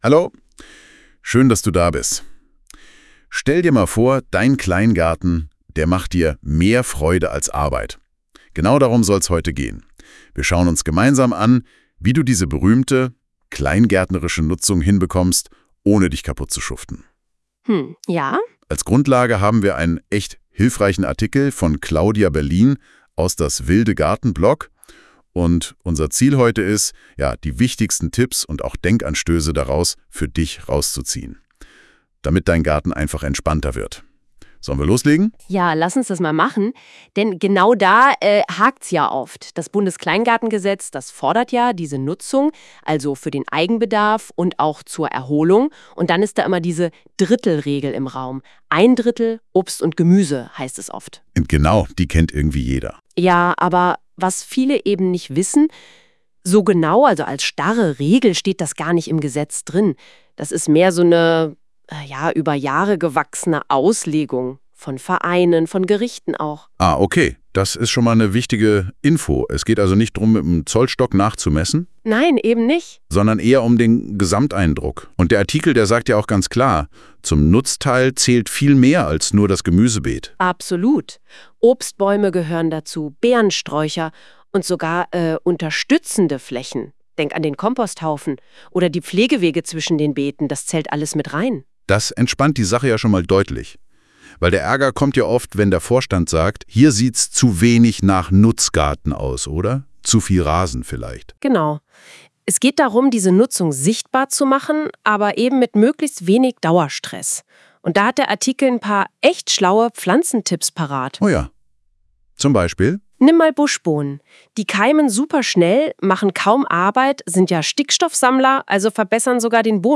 Besonders beeindruckt mich zur Zeit NoteBookLM, mit dem man kurze, dialogische Podcasts herstellen kann – ganz ohne selbst etwas einzusprechen und sogar kostenlos!
Hier ein Beispiel, das einen längeren Artikel zum Thema „Weniger Arbeit mit der kleingärtnerischen Nutzung“ in einen 8-minütigen Podcast verwandelt – mit einer männlichen und einer weiblichen Stimme in einem schon recht natürlichen Ton: